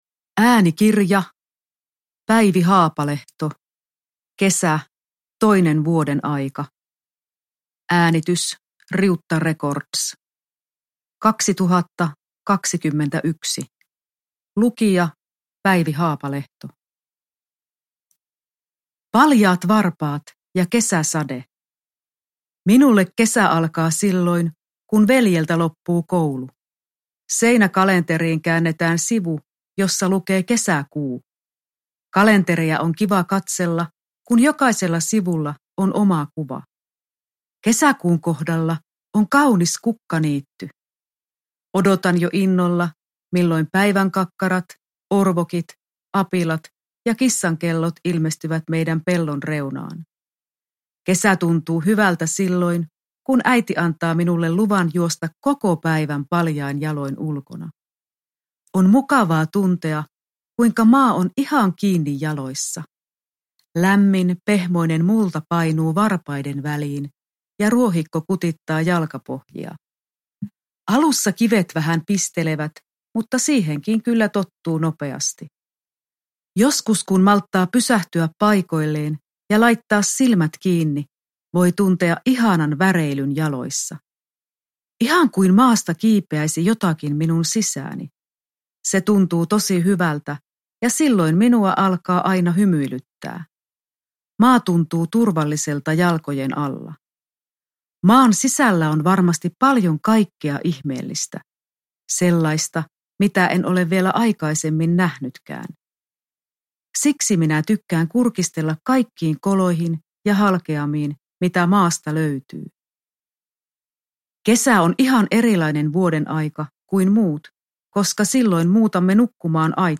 Kesä – Ljudbok – Laddas ner